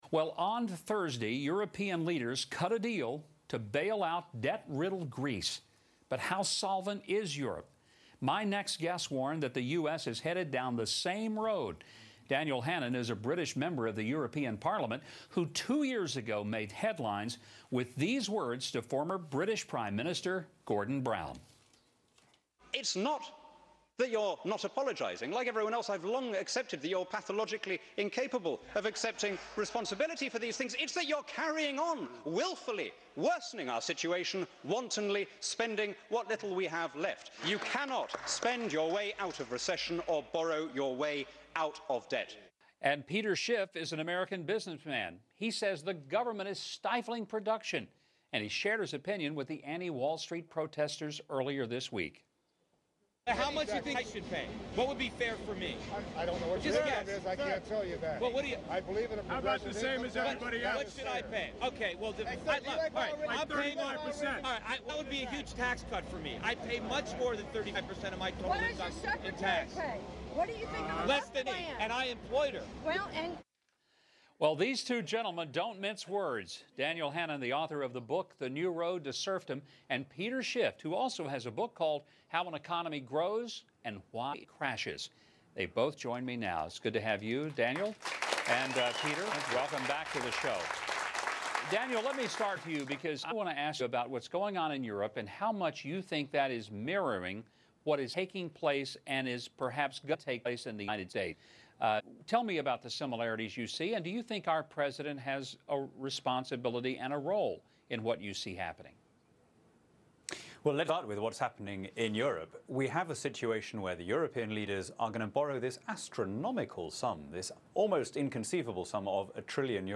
Peter Schiff joins European Parliament Member Daniel Hannan on Huckabee to discuss how increasing government regulation, oversight, and taxes has set back America's economy and created the Occupy Wall Street movenment.